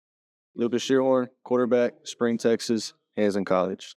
Pronunciation Guide